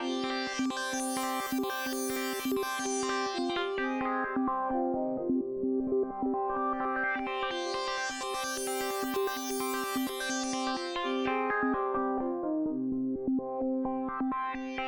01 seq pad B1.wav